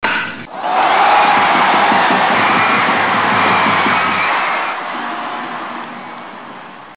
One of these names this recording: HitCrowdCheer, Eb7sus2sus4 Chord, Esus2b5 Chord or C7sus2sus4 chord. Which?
HitCrowdCheer